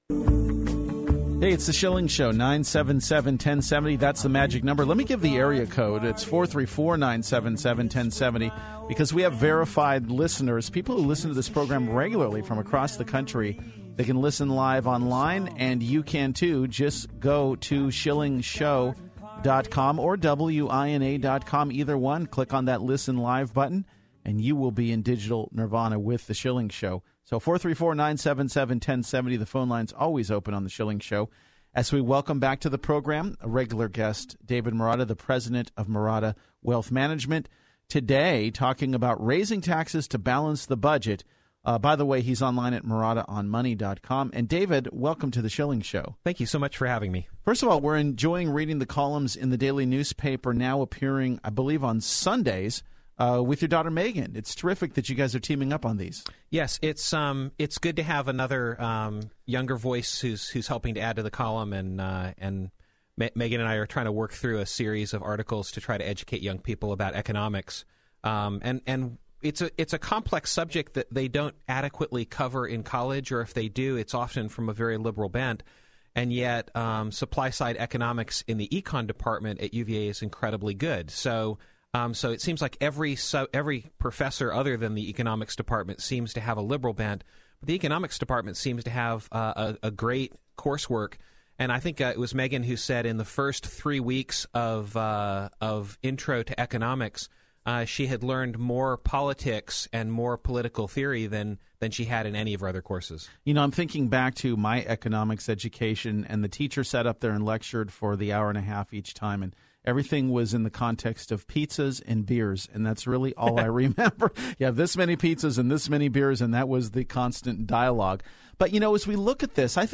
Radio: Should We Raise Taxes to Balance the Budget?